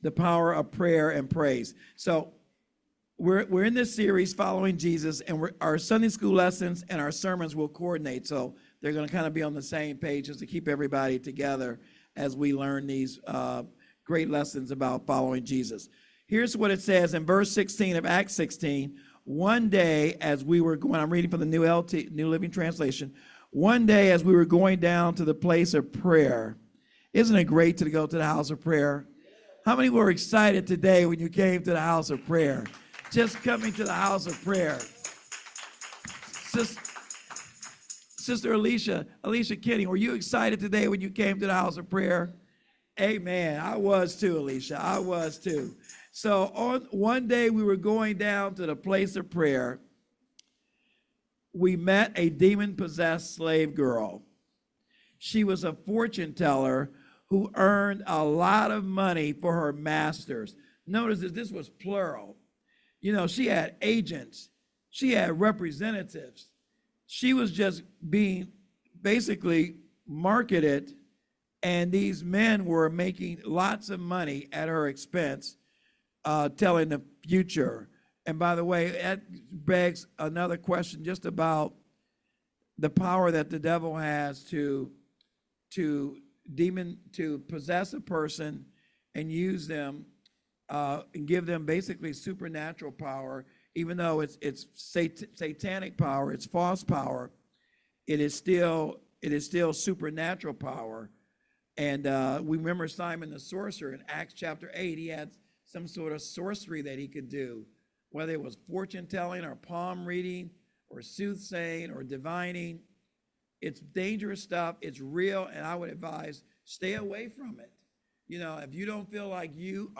Sermon Handout